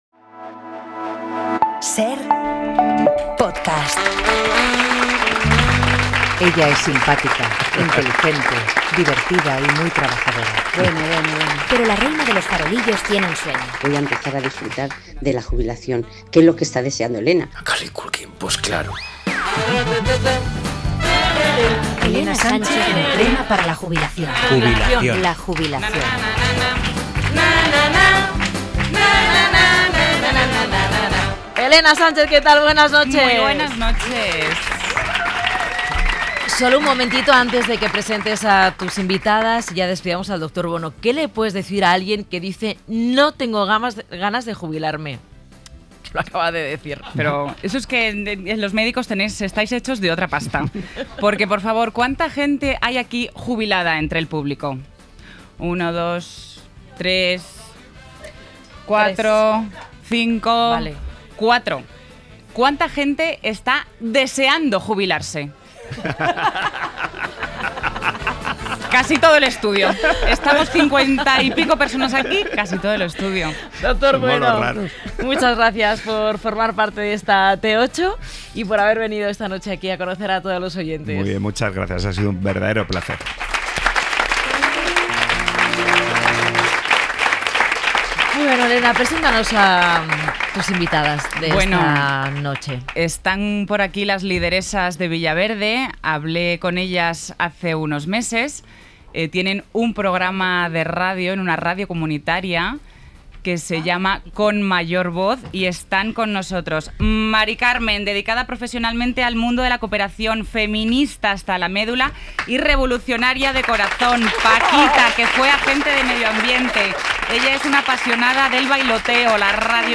Las Lideresas de Villaverde vuelven al Faro para hablar de la radio, la jubilación y las amistades.